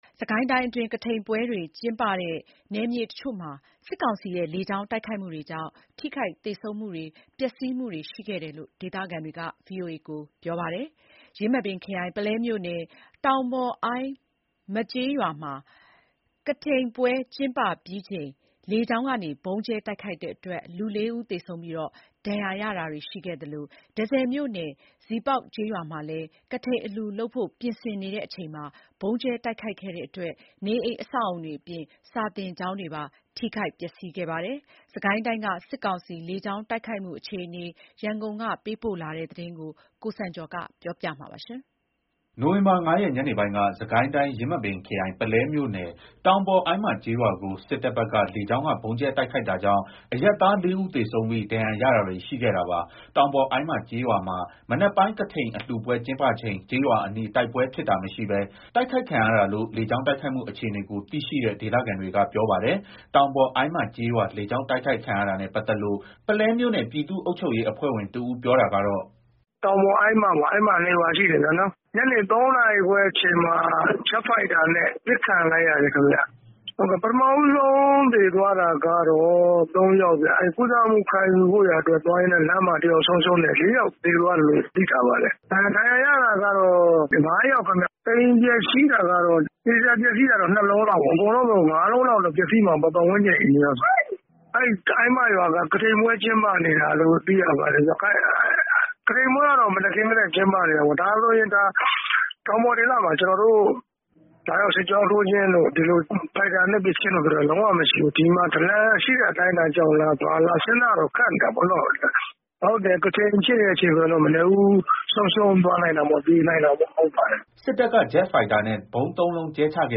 အလားတူပဲ တန့်ဆည်မြို့နယ် ဇီးပေါက်ကျေးရွာမှာလည်း နိုဝင်ဘာ ၄ ရက် ညနေပိုင်းက ကထိန်အလှူပွဲ ပြင်ဆင်နေချိန် စစ်တပ်ဘက်က လေကြောင်းကနေ ၃ ကြိမ် ဗုံးကြဲတိုက်ခိုက်ခဲ့လို့ အလှူပွဲကို ဖျက်သိမ်းပြီး ဒေသခံတွေ ဘေးလွတ်ရာ တိမ်းရှောင်နေရတယ်လို့ ဇီးပေါက်ကျေးရွာသားတဦးက အခုလို ပြောပါတယ်။